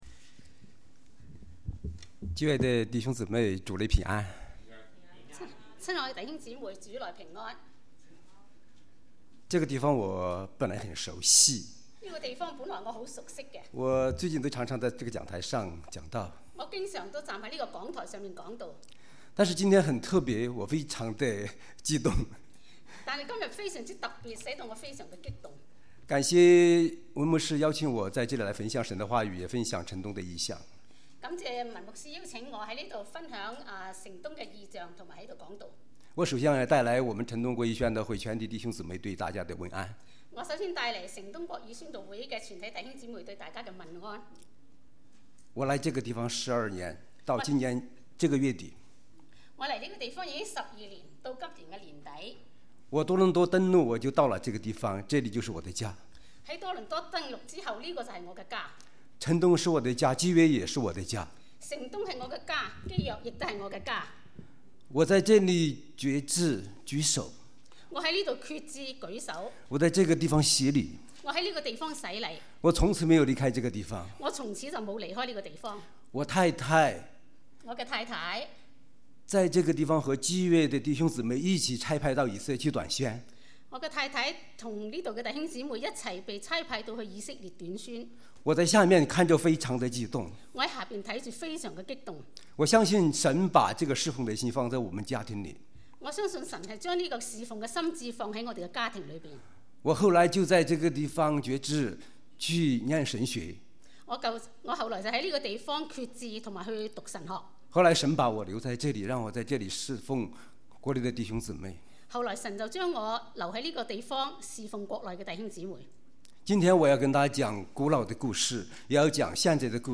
講道 | Sermons | New Covenant Alliance Church (NCAC) 基約宣道會 - Part 64